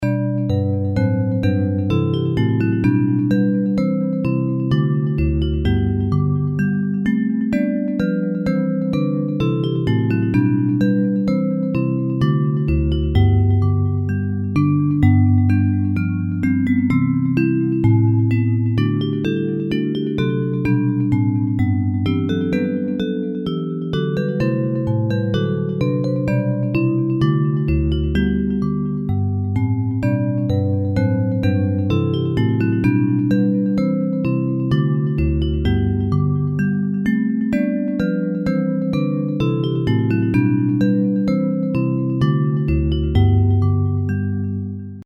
Hymns of praise
Music by: 17th cent. English melody;